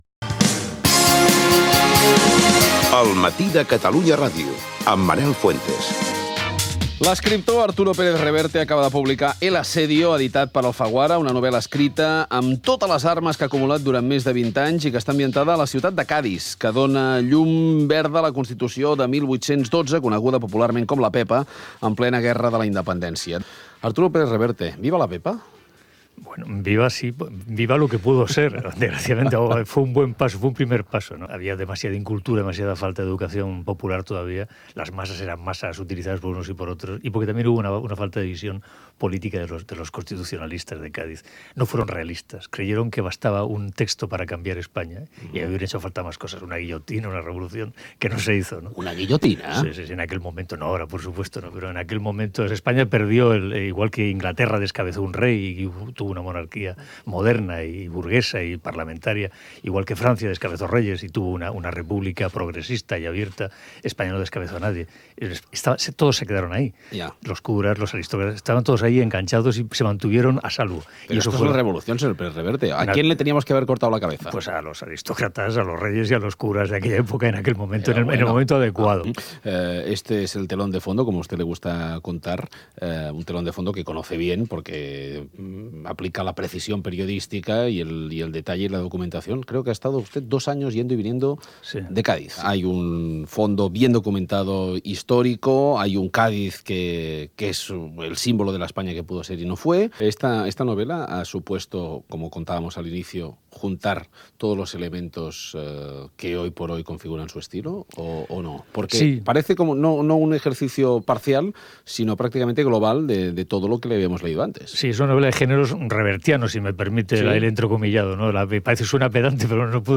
Entrevista a l'escriptor Arturo Pérez-Reverte. Parla de la seva novel·la “El asedio” i de la societat espanyola del segle XIX
Info-entreteniment